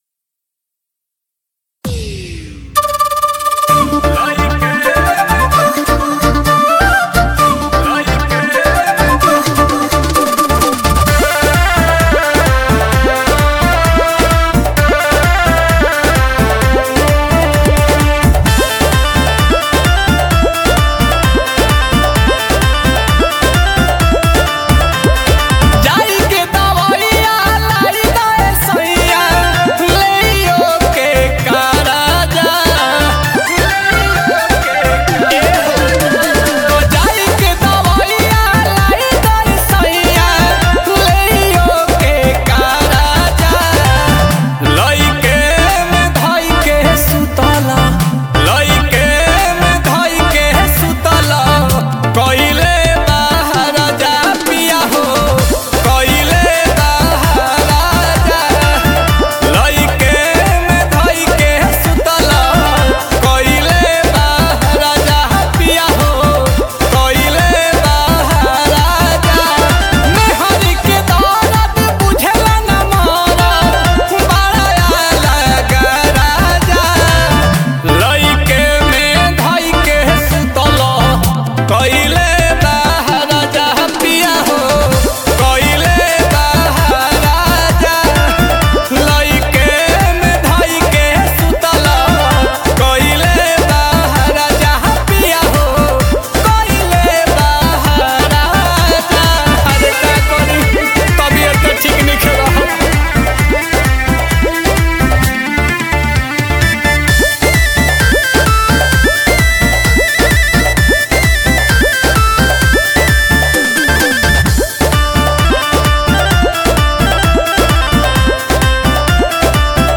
Category: Bhojpuri